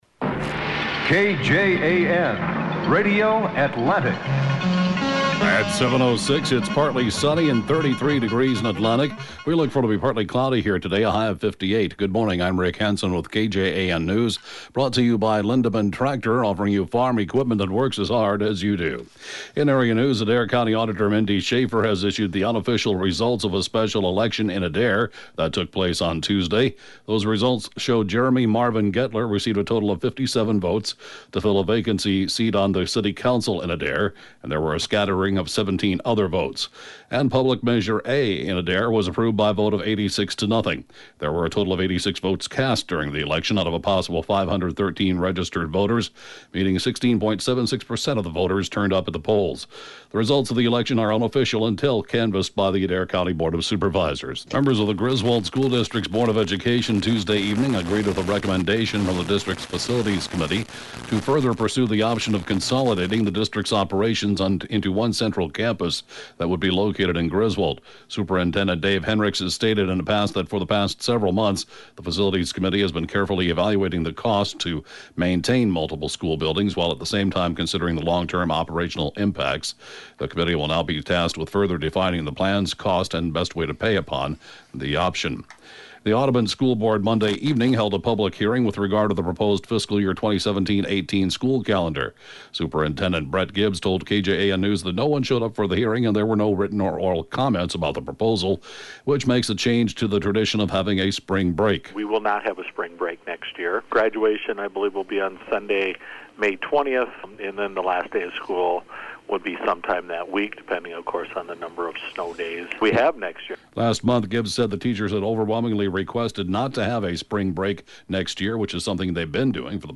News, Podcasts